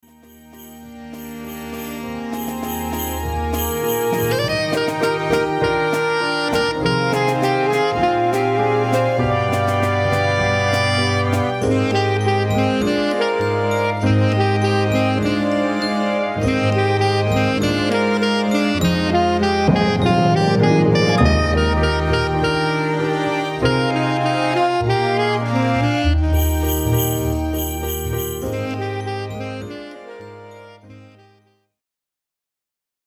Smaltzy backing track included with the price.
Range: low ‘C’ to ‘F’ with octave